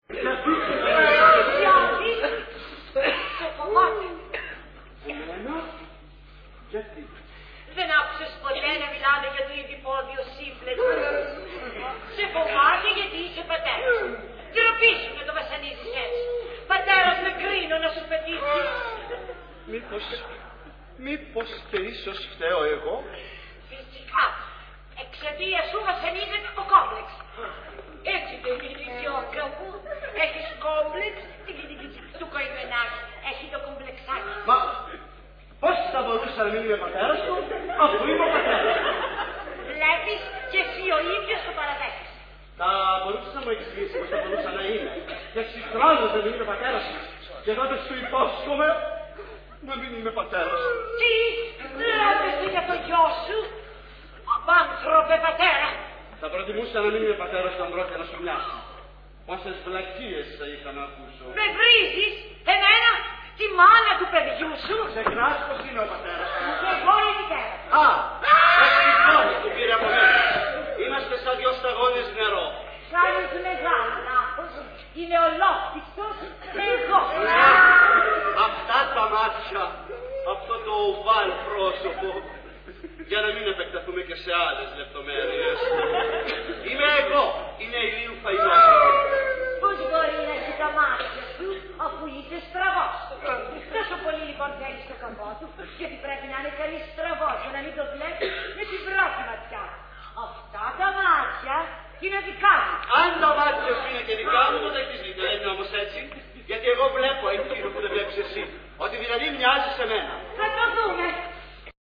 Ηχογράφηση Παράστασης
Αποσπάσματα από την παράσταση 'Ένα ευτυχές γεγονός'.